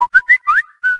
Audio Atumalaca estourado Toque do assobio WhatsApp original
Categoria: Toques